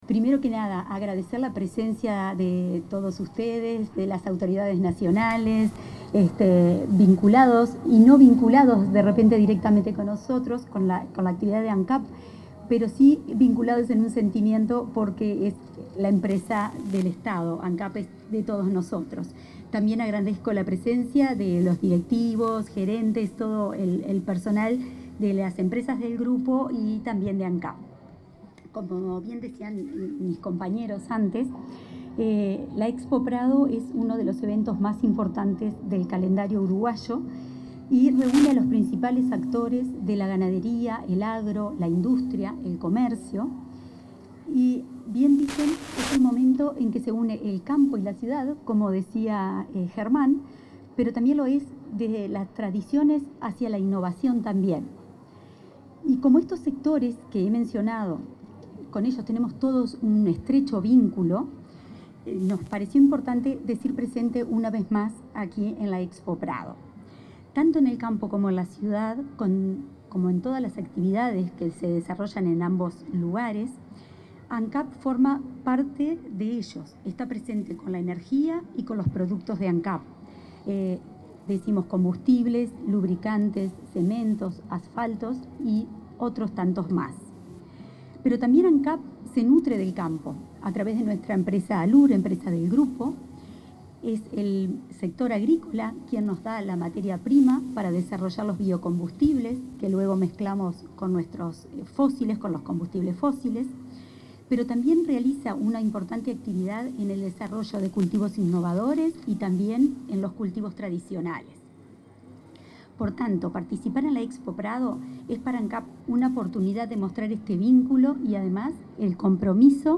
Palabras de la presidenta de Ancap, Cecilia San Román
Palabras de la presidenta de Ancap, Cecilia San Román 09/09/2025 Compartir Facebook X Copiar enlace WhatsApp LinkedIn Durante la apertura de un stand de la Administración Nacional de Combustibles, Alcohol y Pórtland (Ancap) en la Expo Prado 2025, se expresó la presidenta de ese organismo, Cecilia San Román.